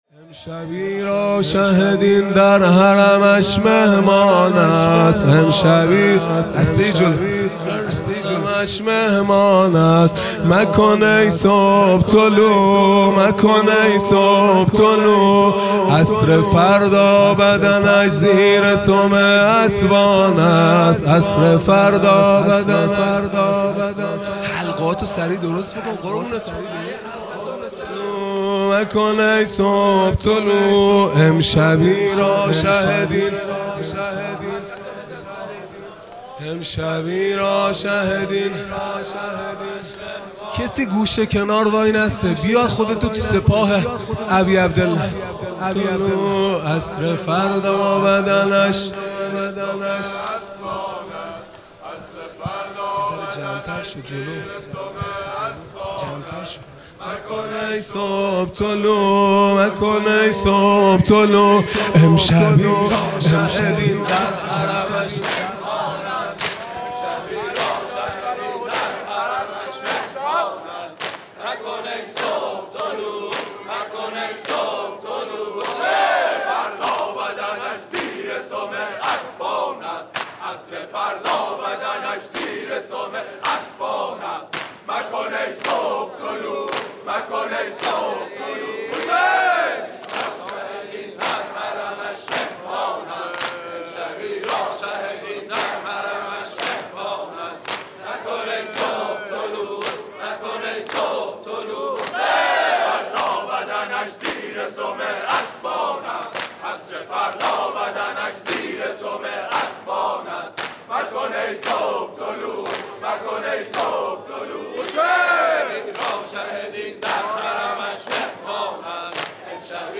دودمه | امشبی را شه دین در حرمش مهمان است | ۵ مرداد ماه ۱۴۰۲
محرم الحرام ۱۴۴5 | شب عاشورا | پنجشنبه 5 مرداد ماه ۱۴۰2